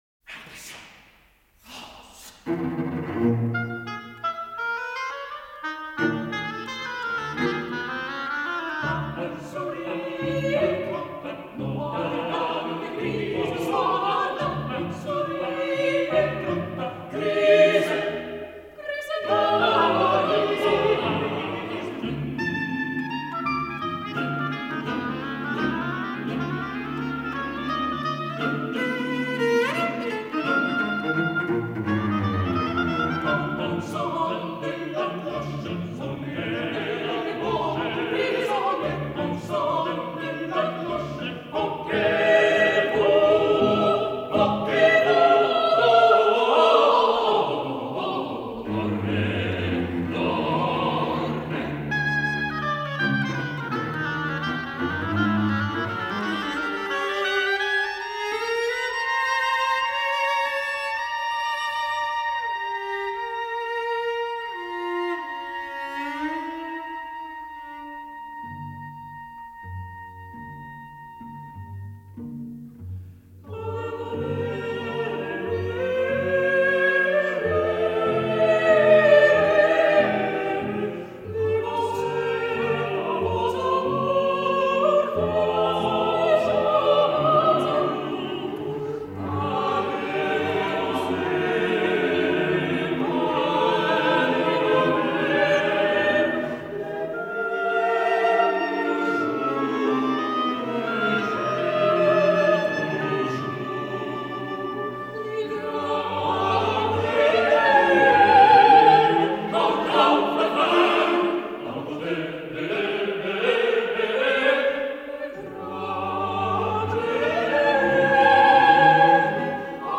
Vokālā mūzika